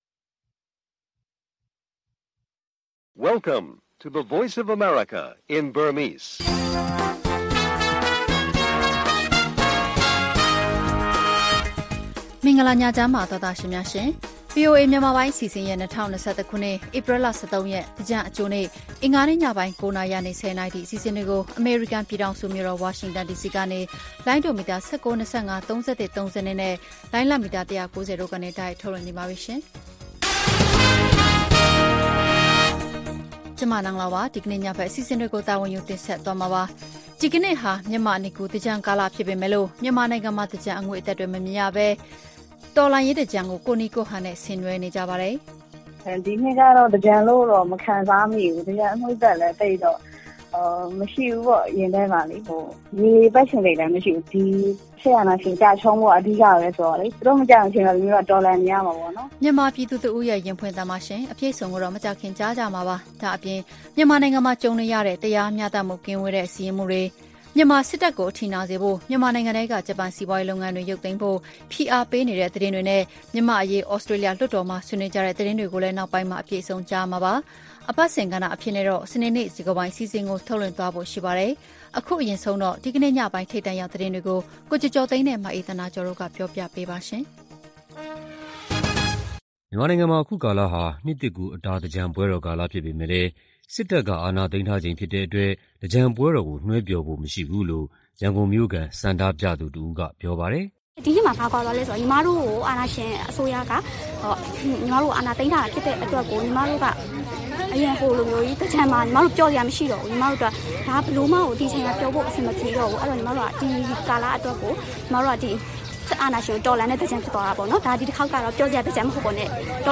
သီတင်းပတ်စဉ် - အင်္ဂါနေ့ညစကားဝိုင်းအစီအစဉ်။